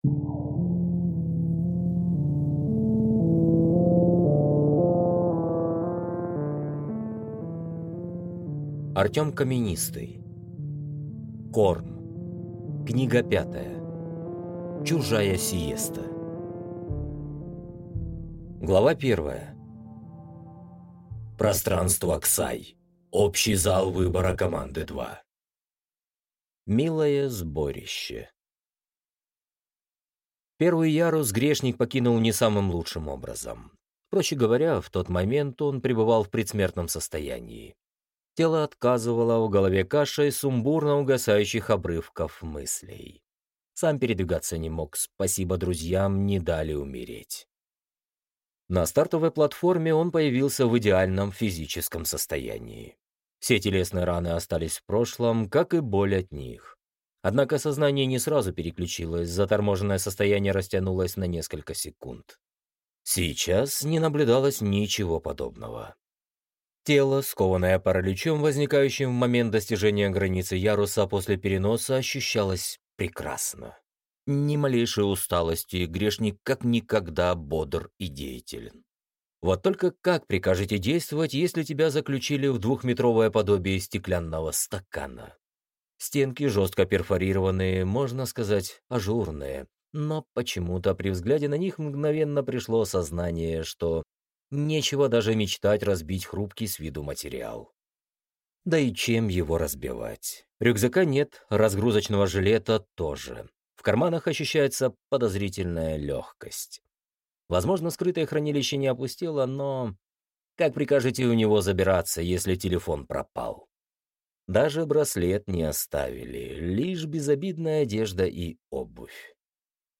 Аудиокнига Чужая сиеста | Библиотека аудиокниг